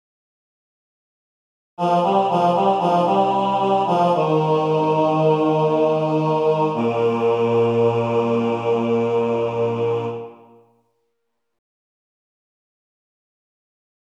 Key written in: E♭ Major
Type: Other male